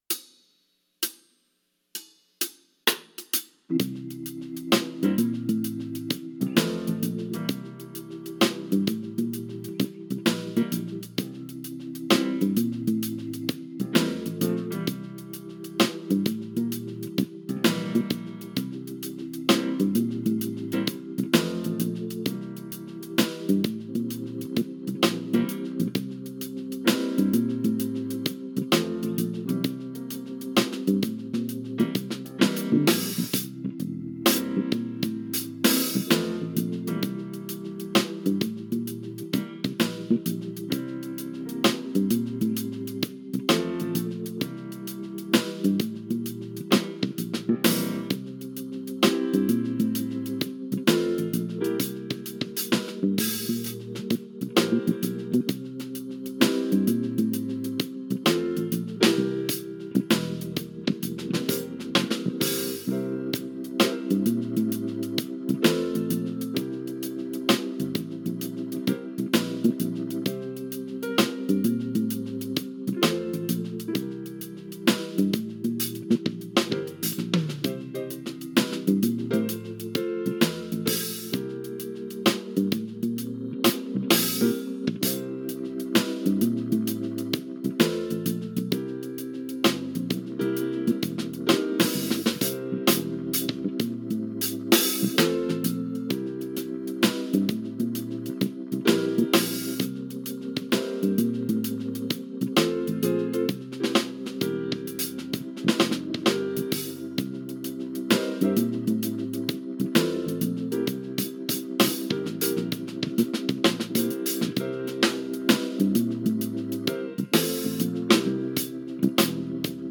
Funky C7 Vamp | Worship Geezer
C7 Funky
Funky-C7-Backing-Track.mp3